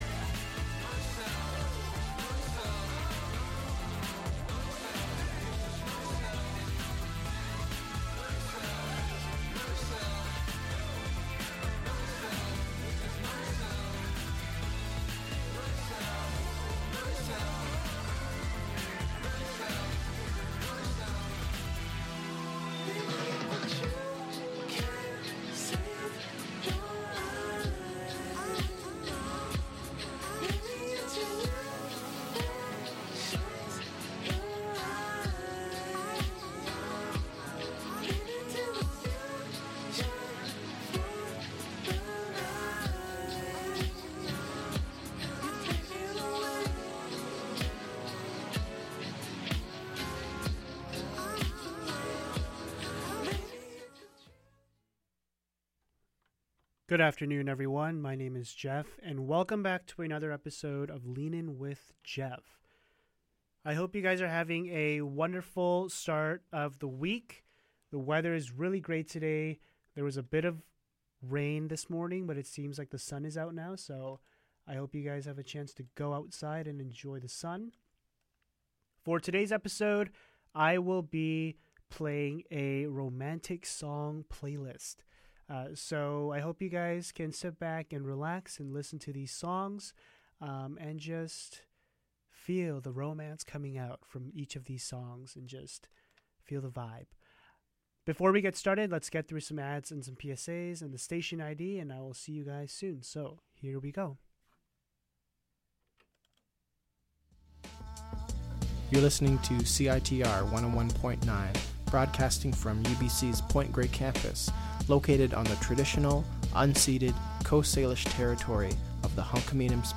In this episode, I’ll be diving into the world of storytelling by sharing an original piece I wrote that blends the genres of fantasy and science fiction.
Tune in for a mix of creative storytelling and captivating m